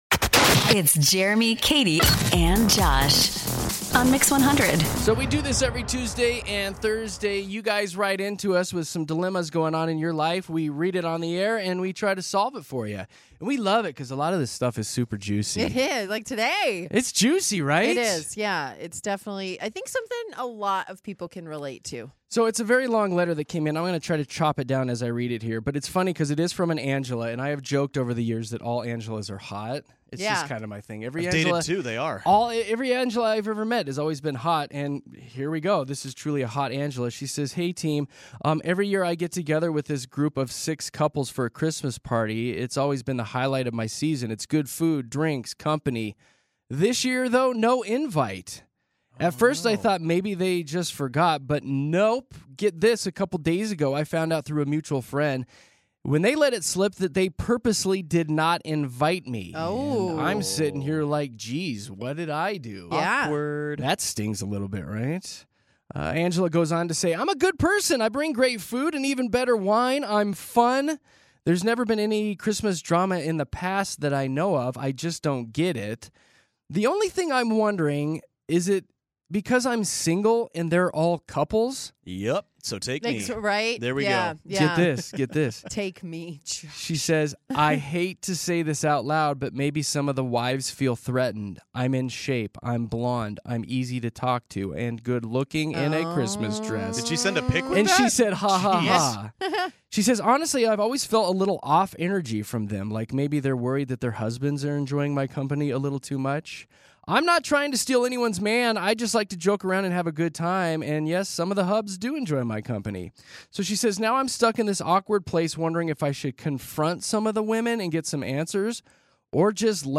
Denver's favorite morning radio show!